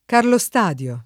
vai all'elenco alfabetico delle voci ingrandisci il carattere 100% rimpicciolisci il carattere stampa invia tramite posta elettronica codividi su Facebook Carlostadio [ karlo S t # d L o ] cogn. — nome umanistico del riformatore A. Bodenstein (m. 1541)